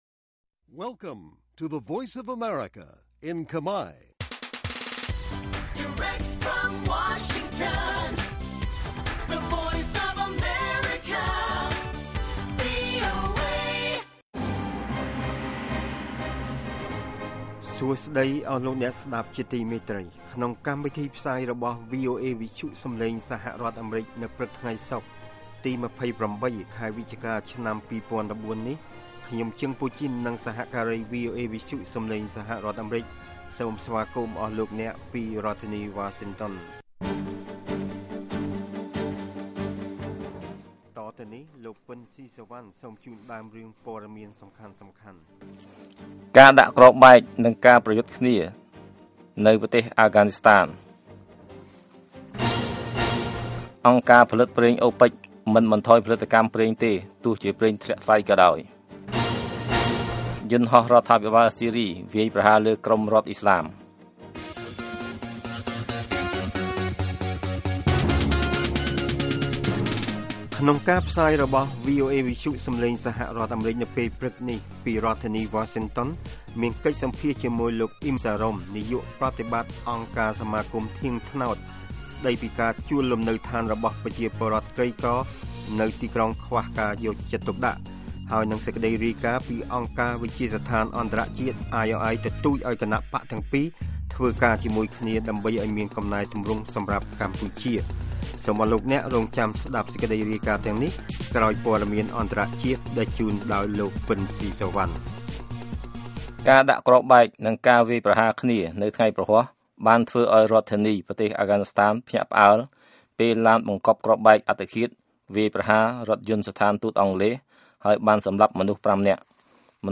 This daily 30-minute Khmer language radio program brings news about Cambodia and the world, as well as background reports, feature stories, and editorial, to Khmer listeners across Cambodia.